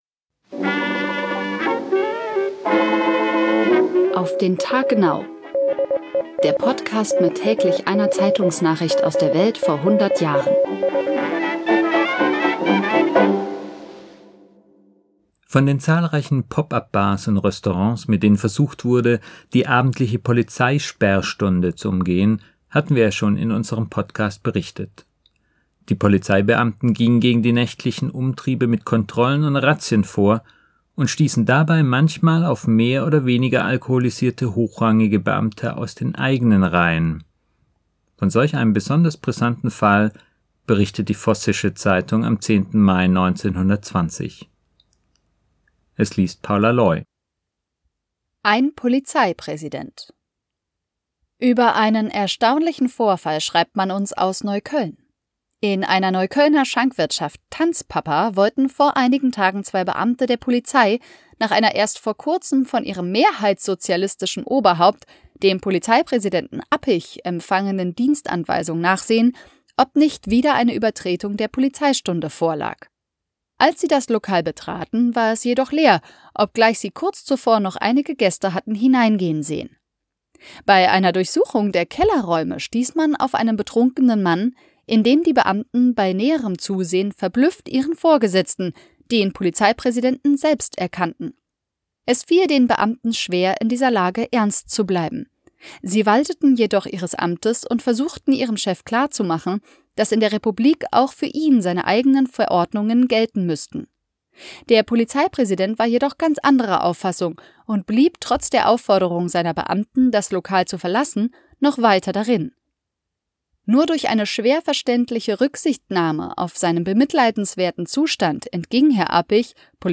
Der Podcast mit täglich einer Zeitungsnachricht aus der Welt vor hundert Jahren